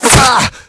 hit_pain_1.ogg